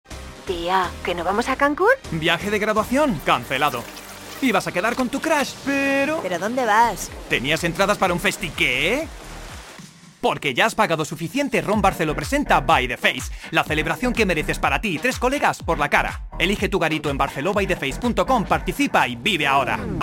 Así suenan algunas de nuestras voces profesionales para vídeos:
Demo-publi-TV-RON-BARCELO-BY-THE-FACE.mp3